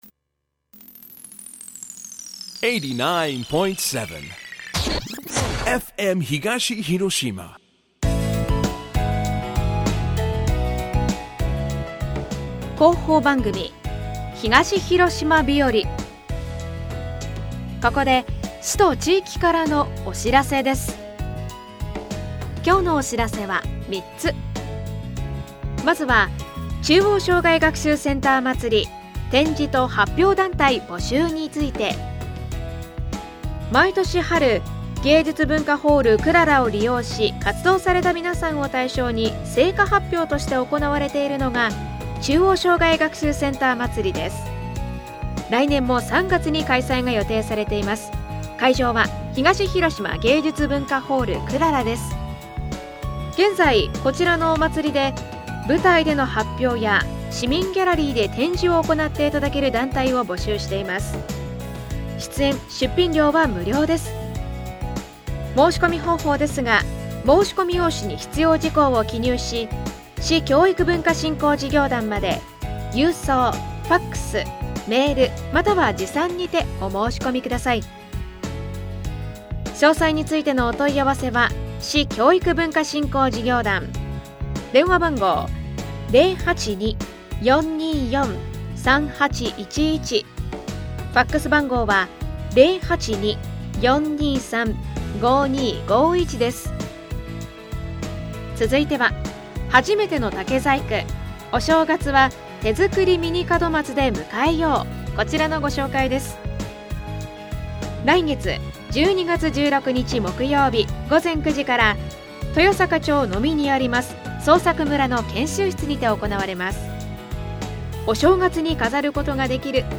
2021年11月17日ＦＭ東広島で放送した 広報番組「東広島日和」です。